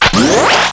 assets/psp/nzportable/nzp/sounds/weapons/raygun/close.wav at b75173a31836b10439a2f9b31aa446ffd52d7430
PSP/CTR: Also make weapon and zombie sounds 8bit